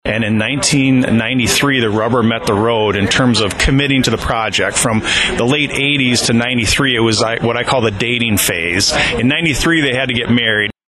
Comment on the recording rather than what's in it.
They celebrated Tuesday with ribbon cuttings